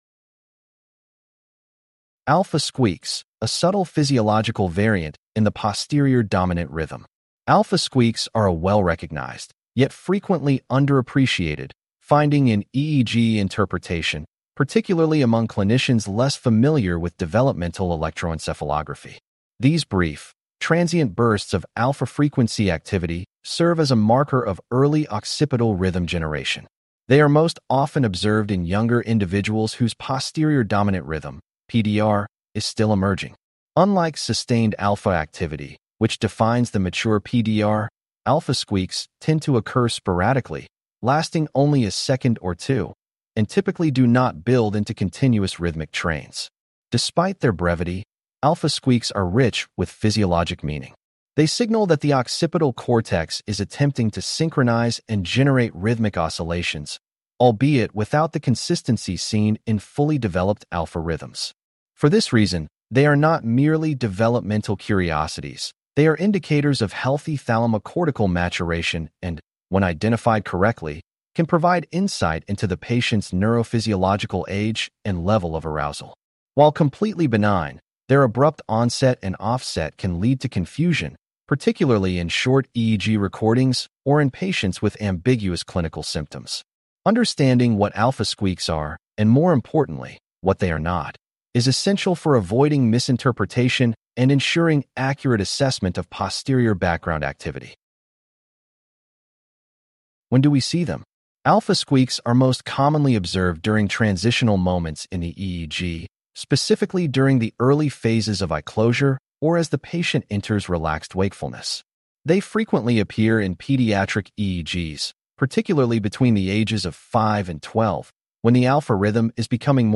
CLICK TO HEAR THIS POST NARRATED These brief, transient bursts of alpha-frequency activity serve as a marker of early occipital rhythm generation.